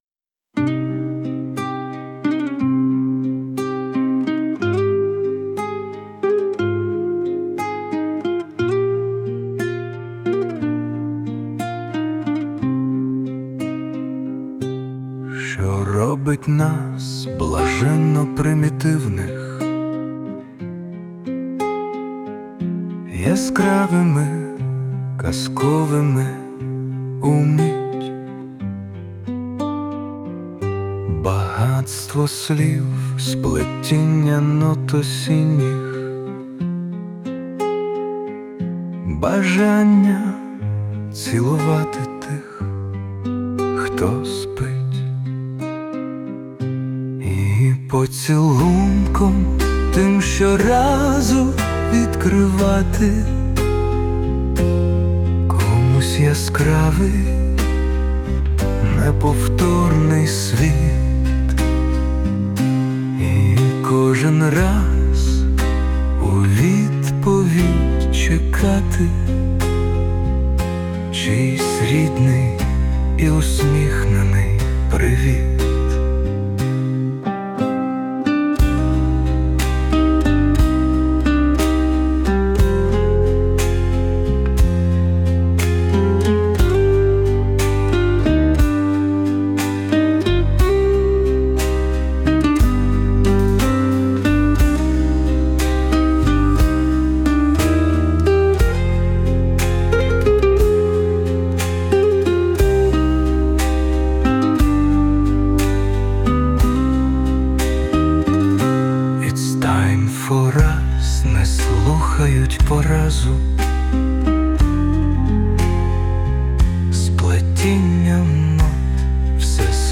Присутня допомога SUNO